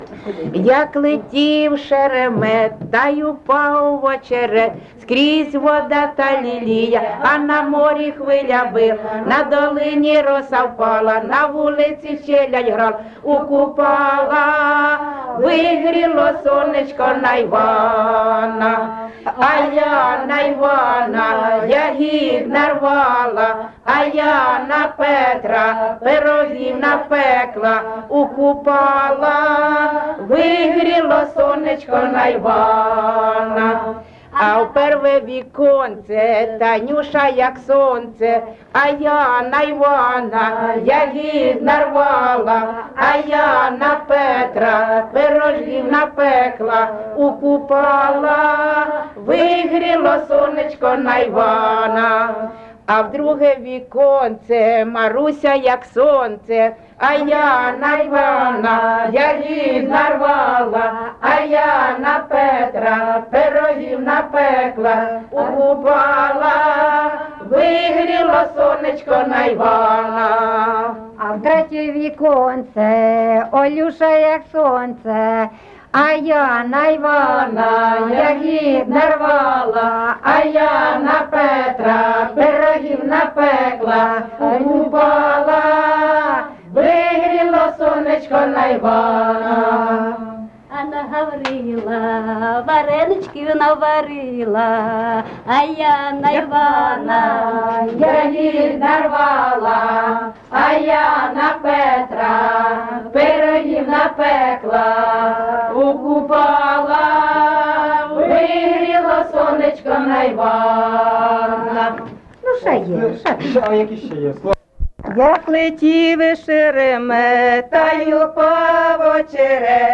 ЖанрКупальські
Місце записус. Лиман, Зміївський (Чугуївський) район, Харківська обл., Україна, Слобожанщина